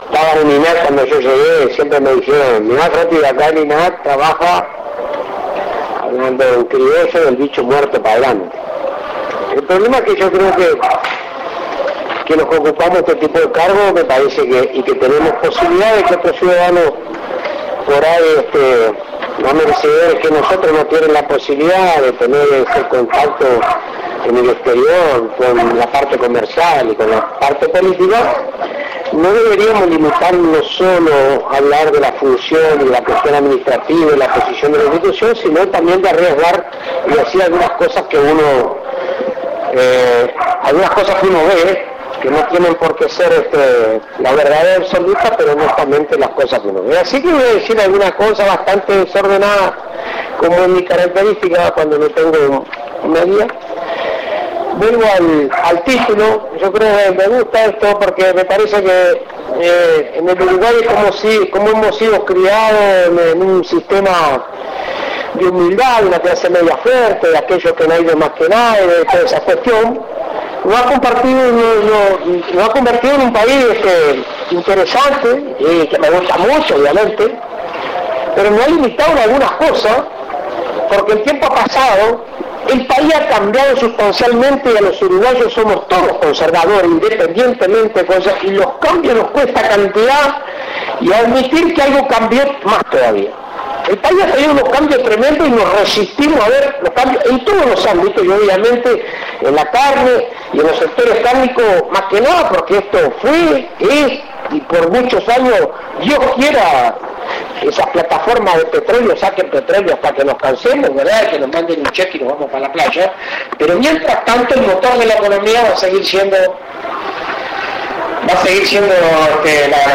Conferencia en Melilla
Coincidiendo con la apertura de Expo Melilla, se realizó una conferencia sobre mercados internacionales.
Audio Dr. Alfredo Fratti. presidente de INAC. mp3